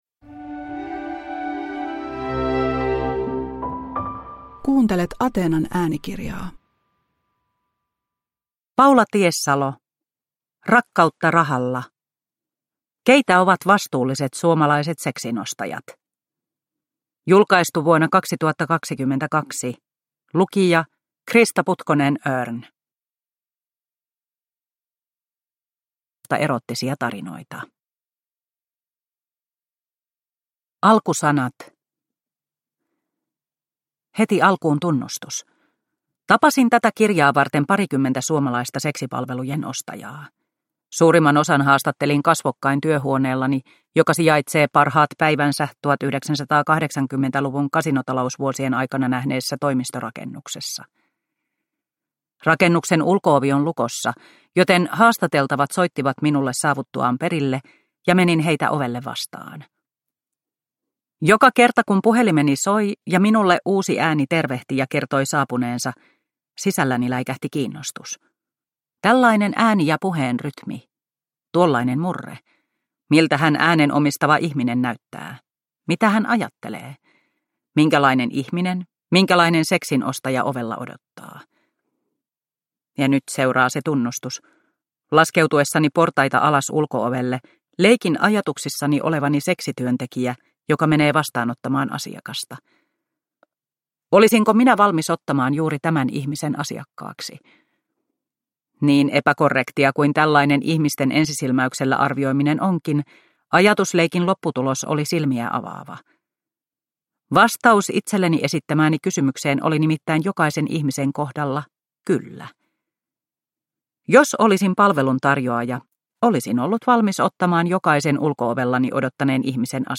Rakkautta rahalla – Ljudbok – Laddas ner